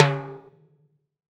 YTIMBALE L1R.wav